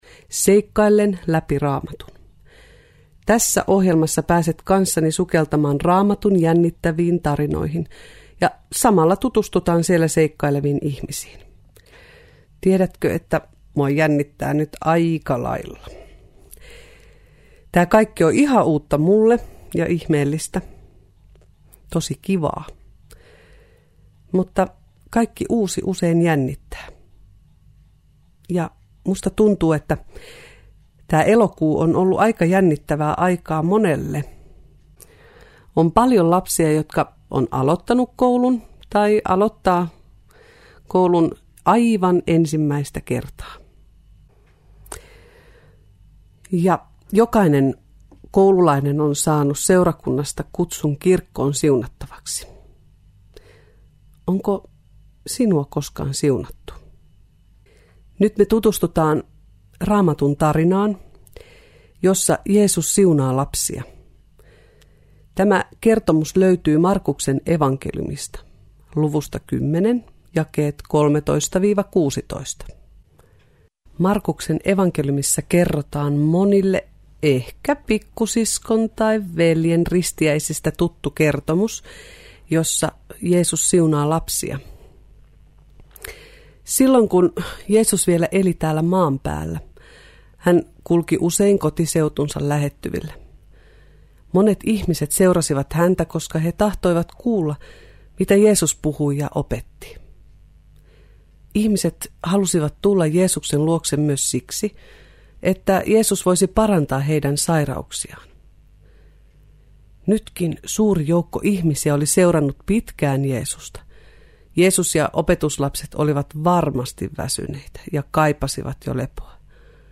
Julkaistu alunperin syksyllä 2010 Radio Deissä lasten Seikkaillen läpi Raamatun -raamattuohjelmana.